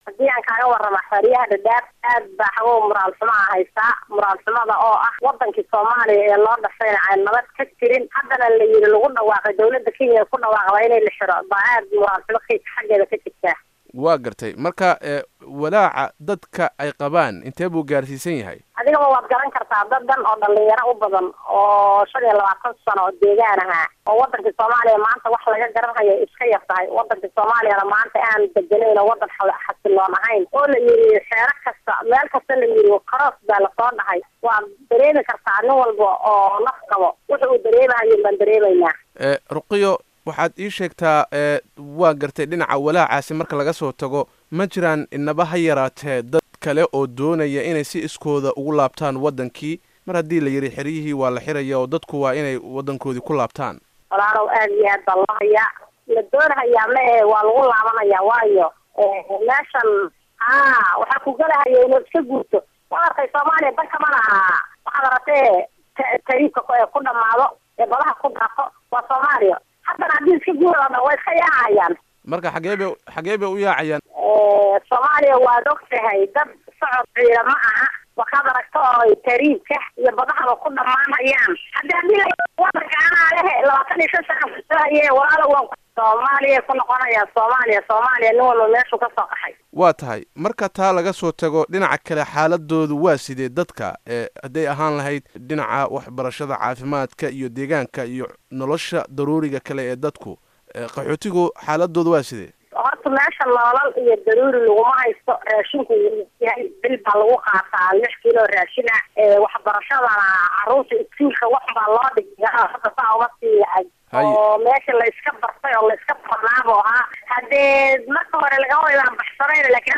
Wareysi: Xaaladda Qaxootiga Dhadhaab